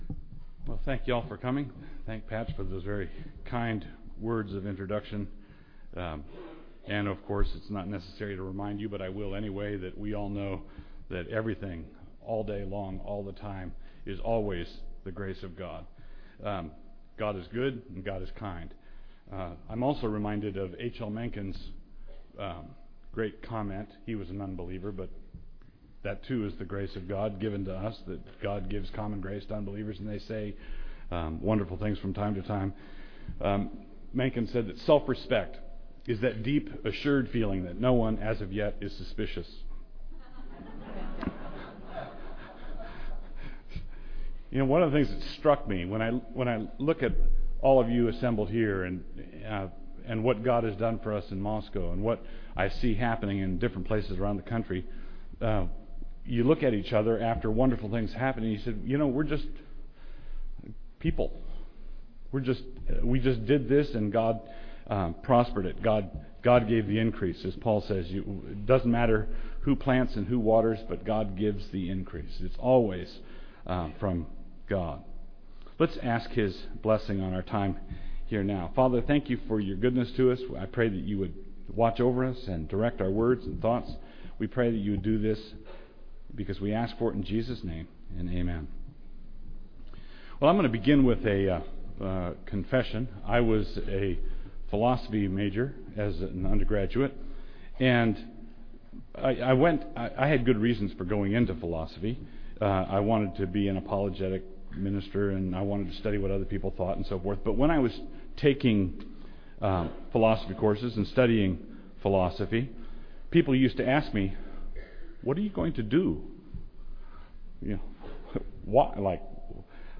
2009 Workshop Talk | 1:04:04 | All Grade Levels, Culture & Faith